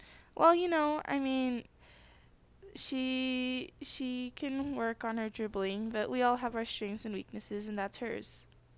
audio examples for Chapter 7: Expressing Positive Assessment
Uses in Conversation